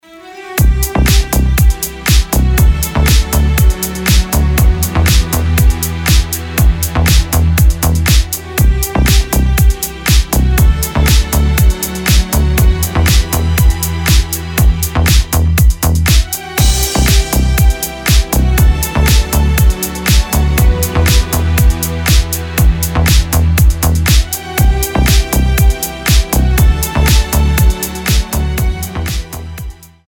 • Качество: 320, Stereo
без слов
красивая мелодия
скрипка
басы
Стиль: deep house, nu disco